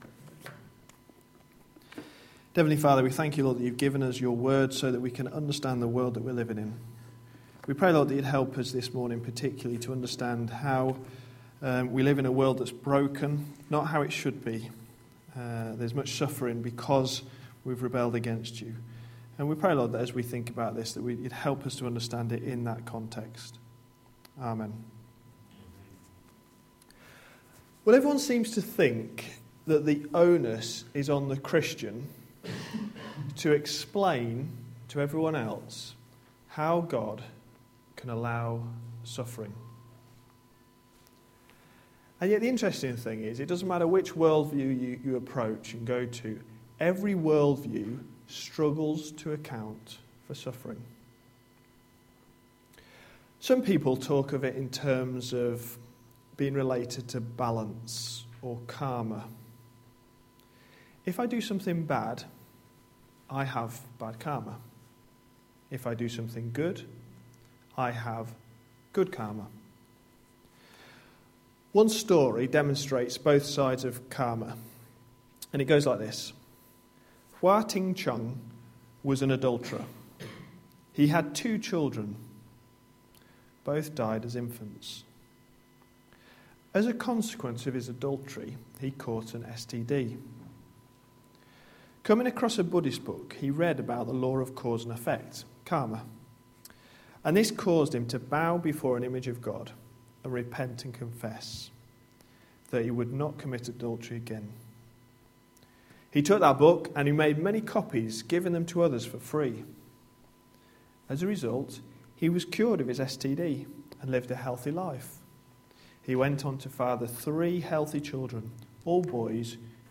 A sermon preached on 16th October, 2011, as part of our Mark series.